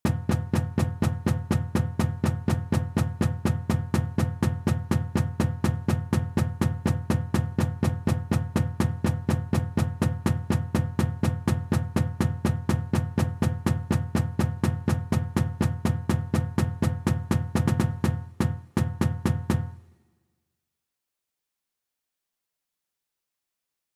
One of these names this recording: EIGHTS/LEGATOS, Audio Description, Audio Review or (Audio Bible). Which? EIGHTS/LEGATOS